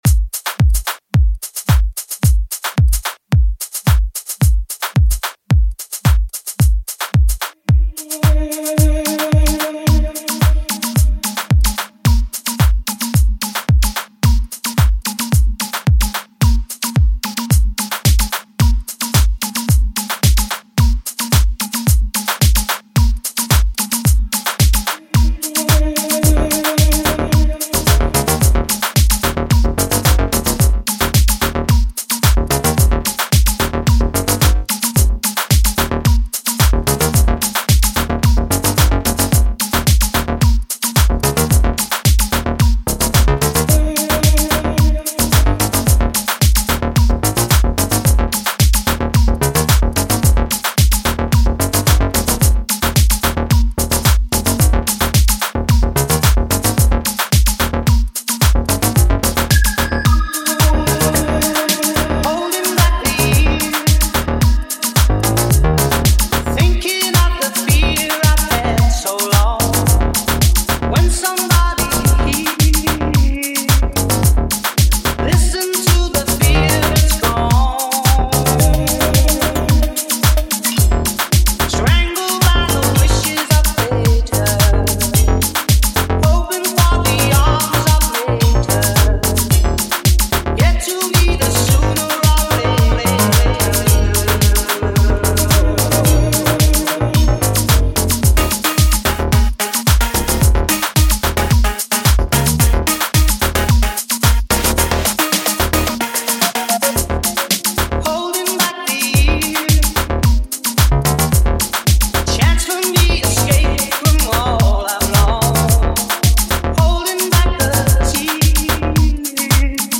Hope enjoy my House remix